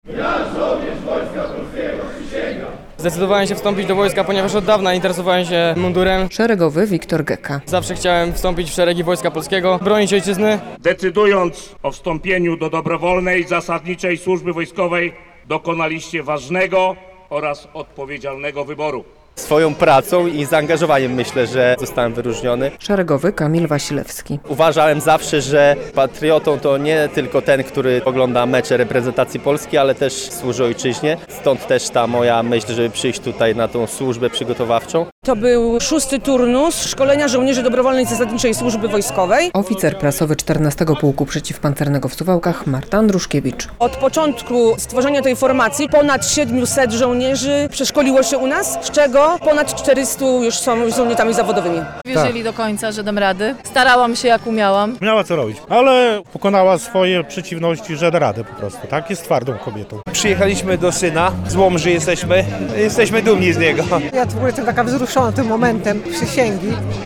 54 żołnierzy ochotników, w tym 10 kobiet, złożyło w sobotę (10.08) na placu apelowym 14. Pułku Przeciwpancernego w Suwałkach uroczystą przysięgę wojskową.
Przysięga żołnierzy ochotników - relacja